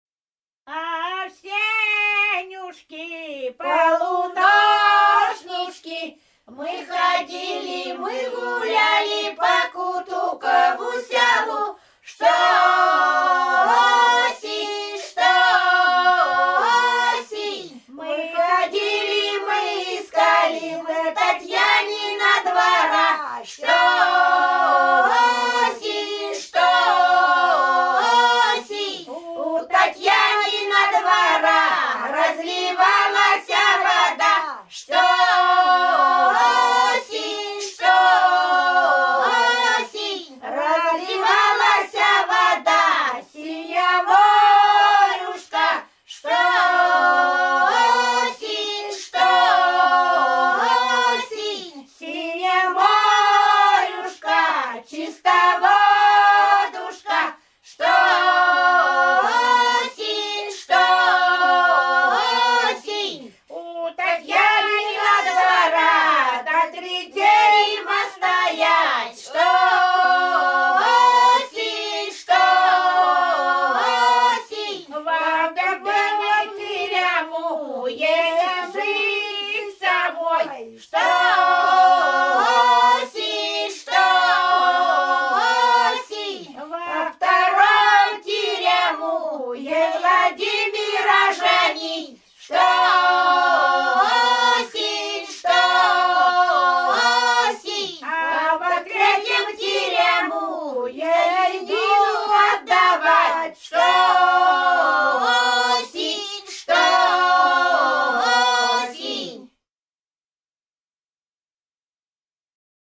Рязань Кутуково «Авсенюшки», новогодняя поздравительная.